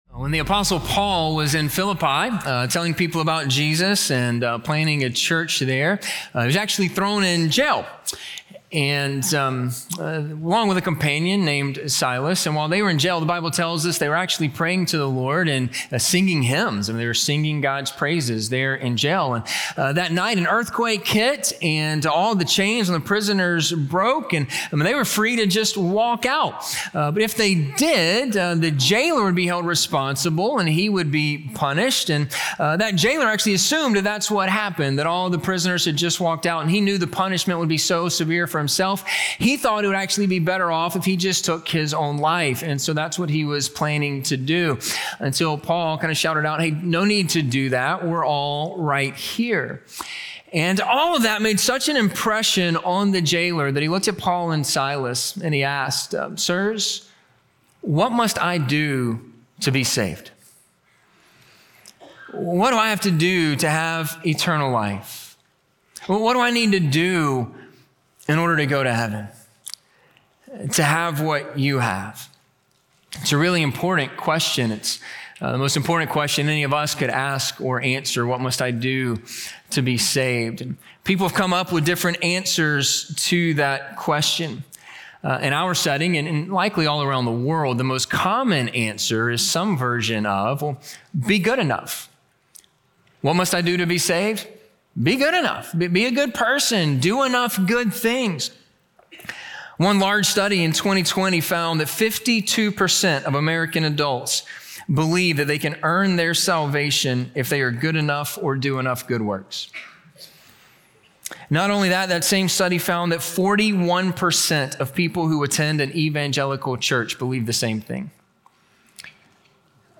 What Must I Do to Be Saved? - Sermon - Ingleside Baptist Church